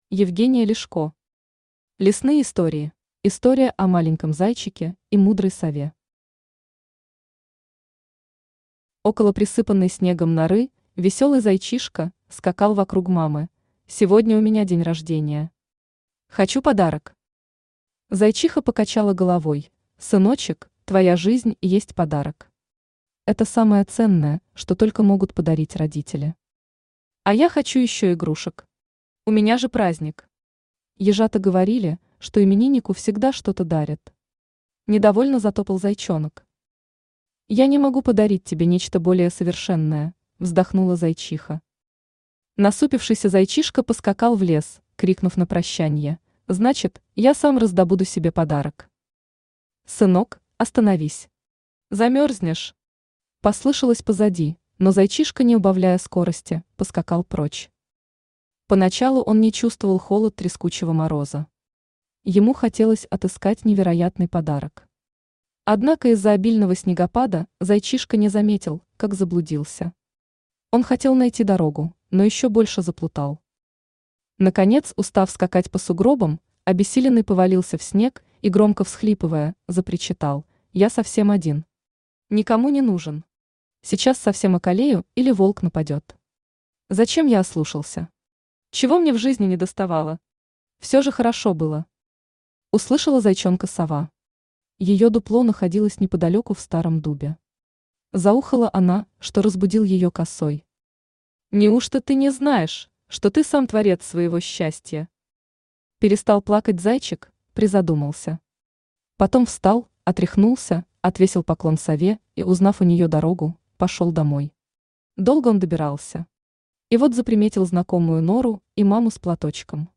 Aудиокнига Лесные истории Автор Евгения Ляшко Читает аудиокнигу Авточтец ЛитРес.